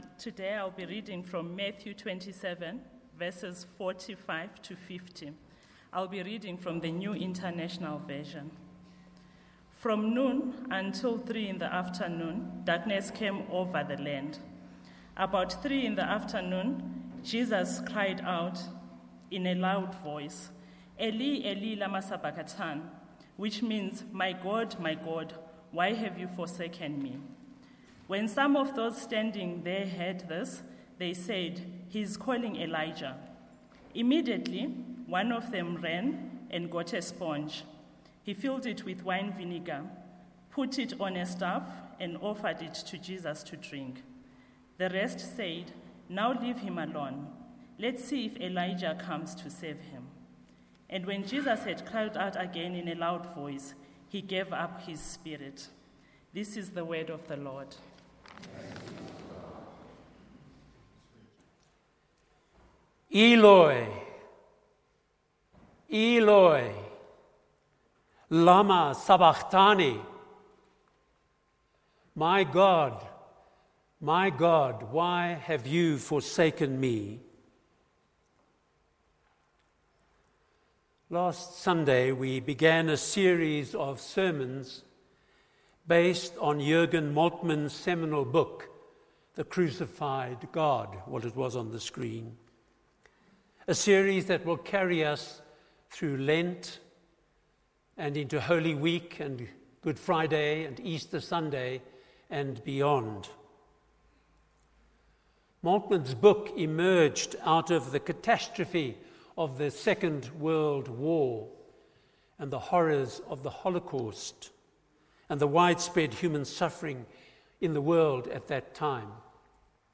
Sermons
Fusion Service from Trinity Methodist Church, Linden, Johannesburg